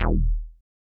bass note01.wav